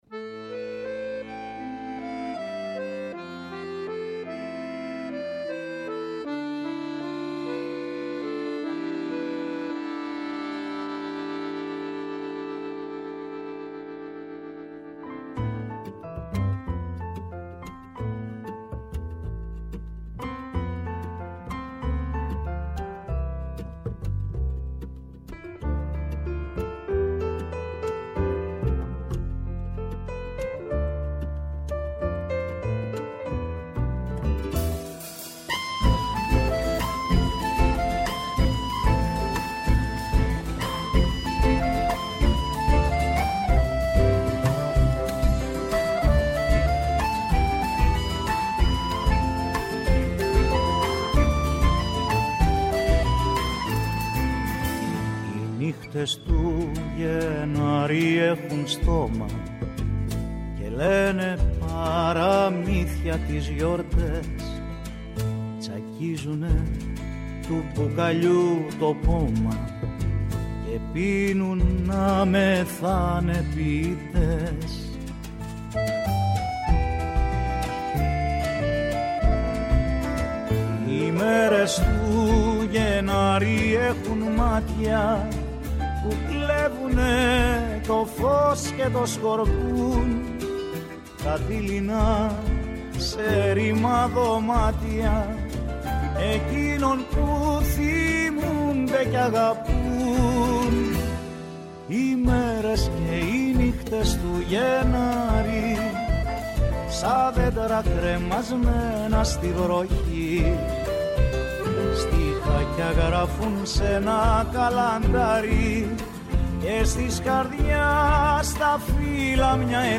-ο Νίκος Βούτσης, πρώην Πρόεδρος της Βουλής
-ο Γιώργος Βασιλειάδης, αναπληρωτής γραμματέας ΣΥΡΙΖΑ
Κάθε Παρασκευή 11:00-12:00 , στο Πρώτο Πρόγραμμα της Ελληνικής Ραδιοφωνίας. «Με τούτα και μ’ εκείνα», τελειώνει η εβδομάδα τις καθημερινές.